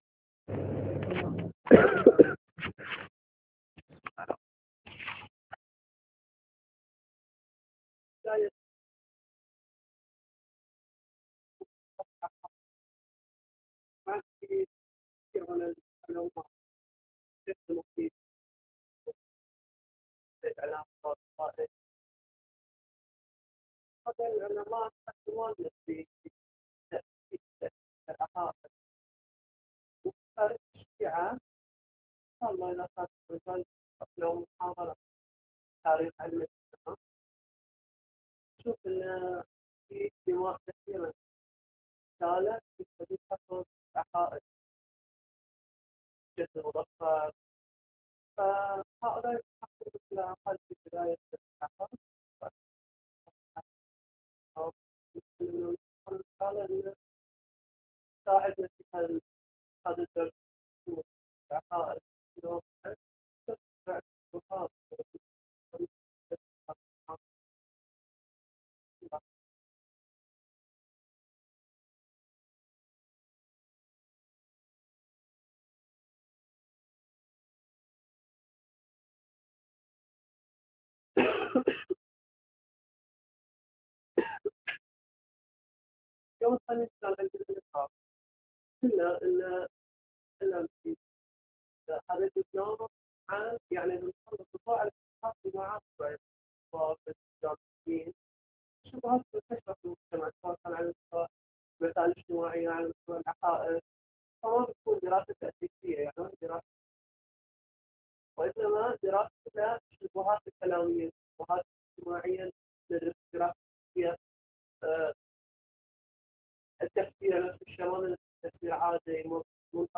شبهات کلامیة معاصرة (التوحید): الدرس الأول